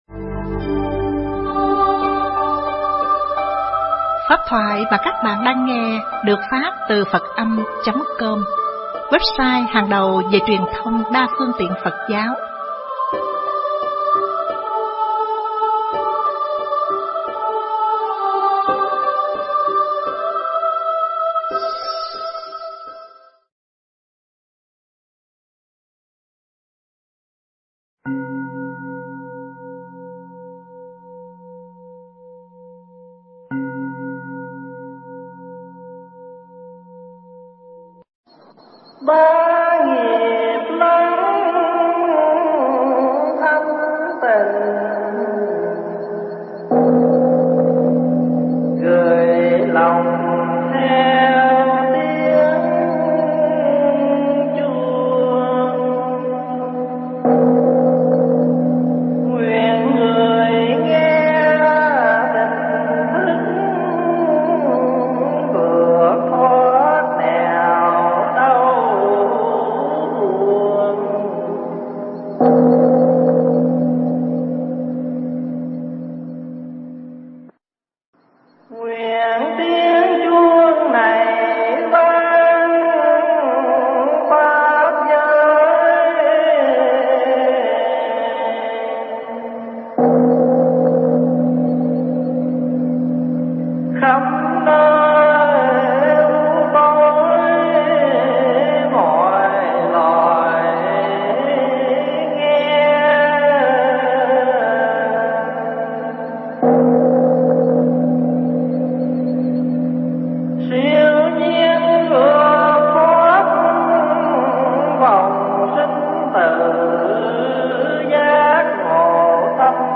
Nghe Mp3 thuyết pháp Thánh Hiệu Dược Sư Phần 1 – Đại Đức Thích Pháp Hòa
Tải mp3 Thuyết Pháp Thánh Hiệu Dược Sư Phần 1 – Đại Đức Thích Pháp Hòa thuyết giảng tại Chùa Phổ Minh (Windsor, Ontario, Canada), ngày 18 tháng 2 năm 2017